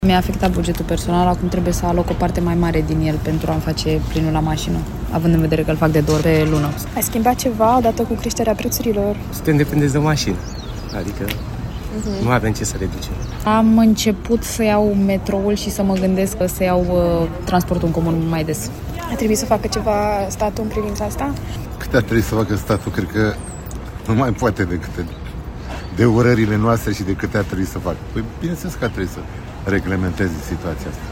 Statul ar trebui să reglementeze creșterea de preț a carburanților, ne-a declarat un bărbat din București.
20mar-13-Voxuri-la-pompa.mp3